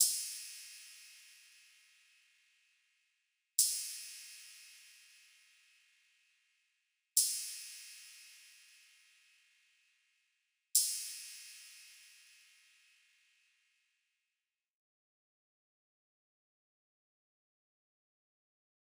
drill (!)_[PBS] Clean Crash.wav